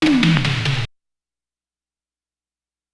86 Roll.wav